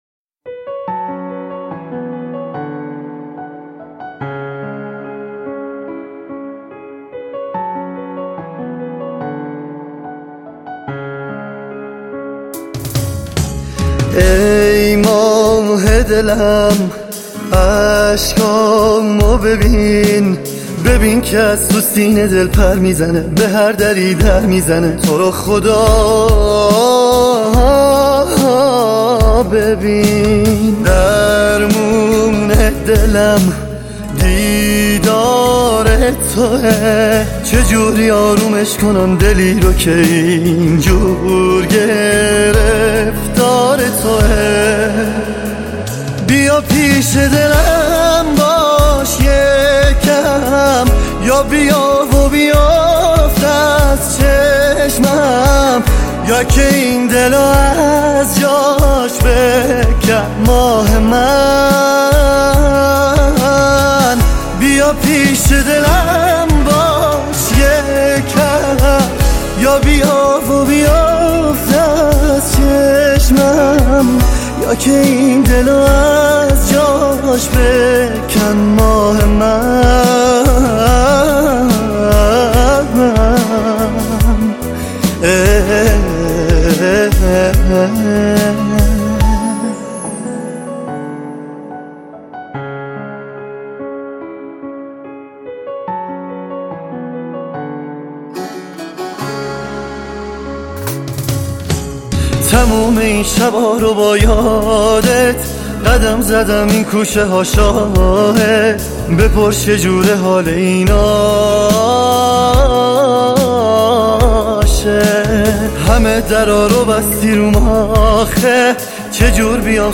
خوانندگی در سبک پاپ